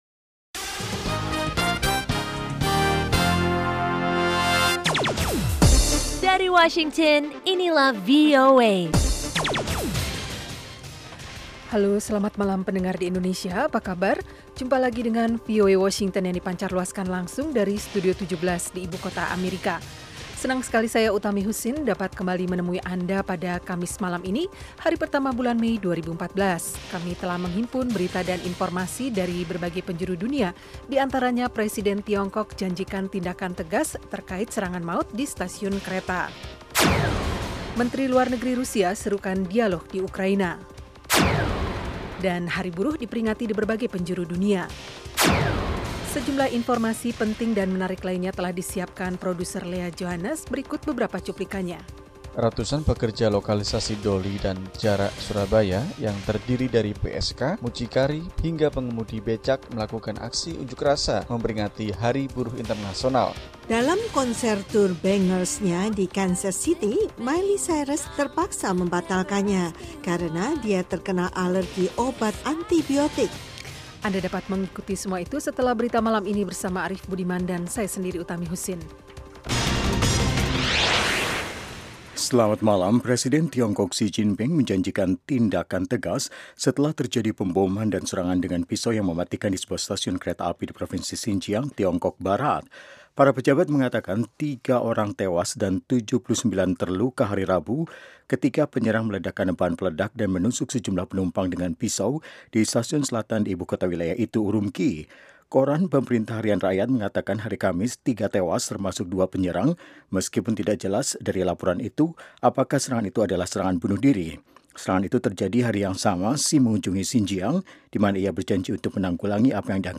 Selain padat dengan informasi, program ini sepanjang minggu menyuguhkan acara yang bernuansa interaktif dan penuh hiburan.
Kami menyajikan berbagai liputan termasuk mengenai politik, ekonomi, pendidikan, sains dan teknologi, Islam dan seputar Amerika. Ada pula acara musik lewat suguhan Top Hits, music jazz dan country.